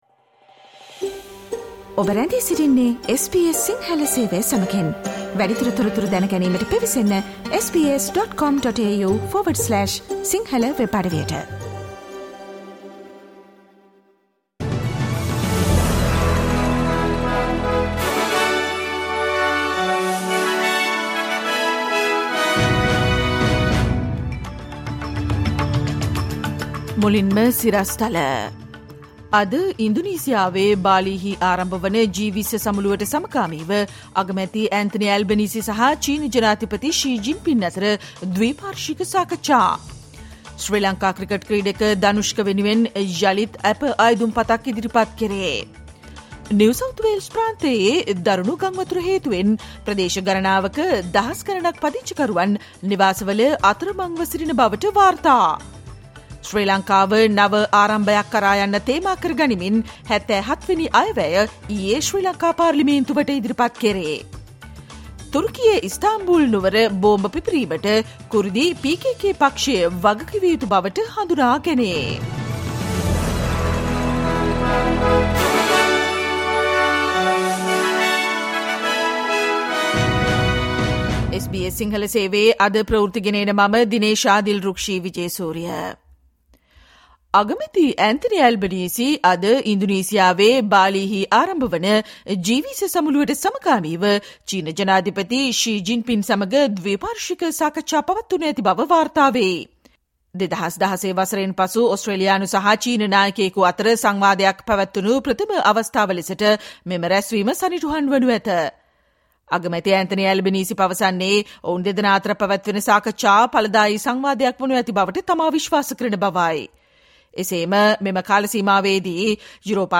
Listen to the SBS Sinhala Radio news bulletin on Tuesday 15 November 2022